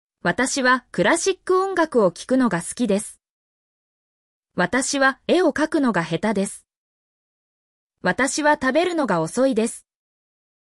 mp3-output-ttsfreedotcom-23_b95fSrDB.mp3